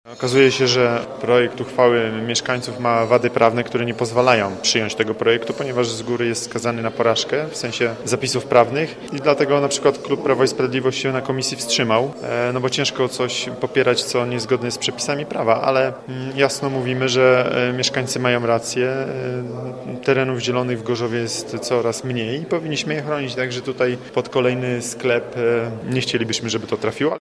Okazało się jednak, że inicjatywa mieszkańców jest niezgodna z prawem. Mówi wiceprzewodniczący rady miasta z Prawa i Sprawiedliwości Sebastian Pieńkowski: